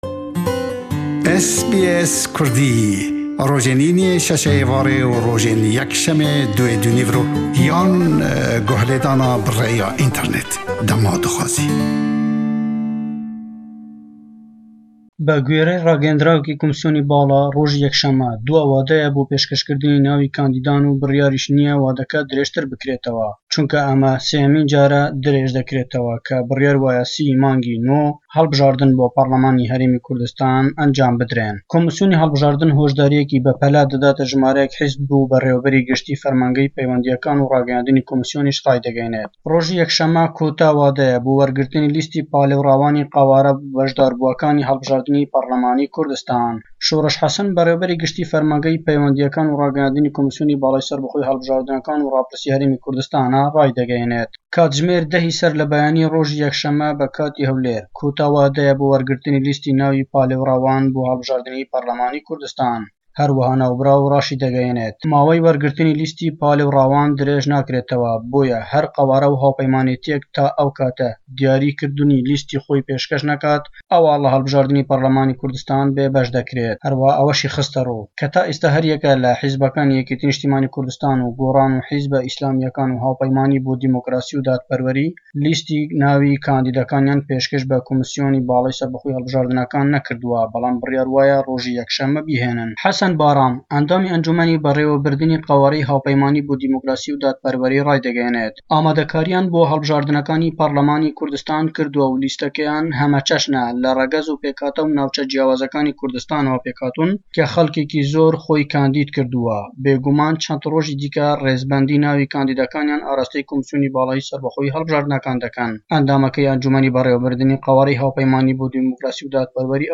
Raporat